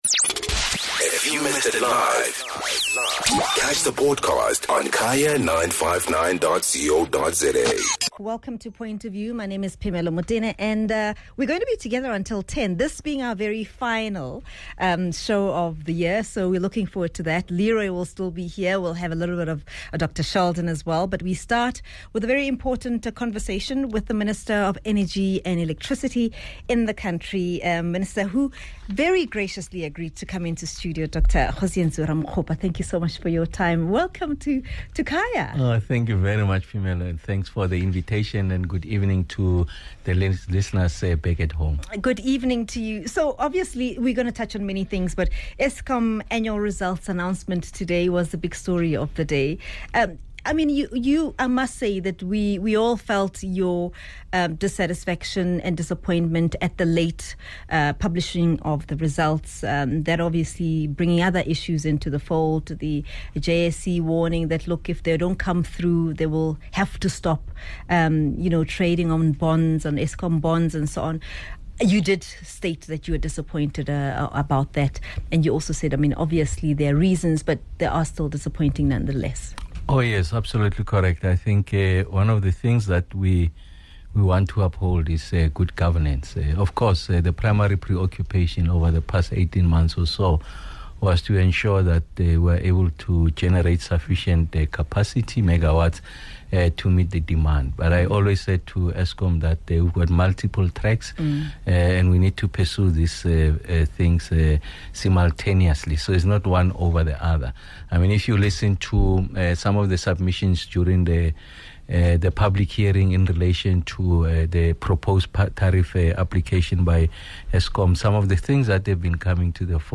Minister of Electricity and Energy, Dr Kgosientsho Ramokgopa joins us in studio as our final guest for 2024 to discuss the country’s electricity outlook.